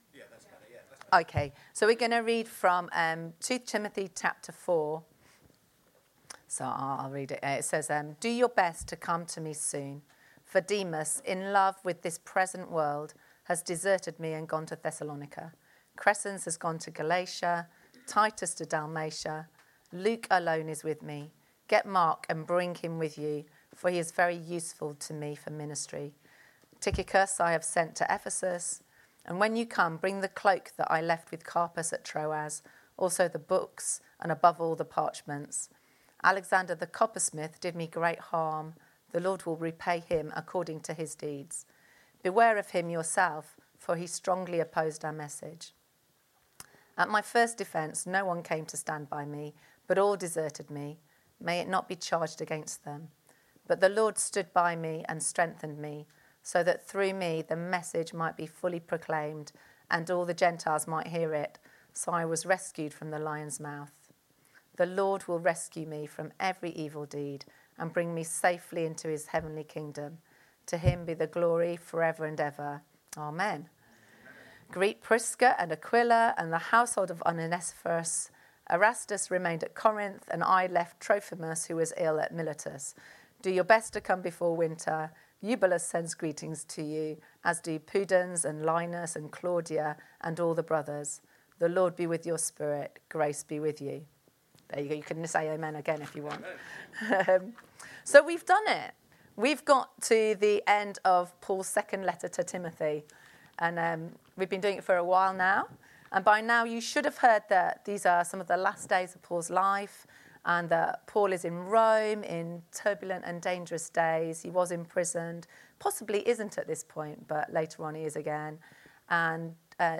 Download Partnership in the gospel | Sermons at Trinity Church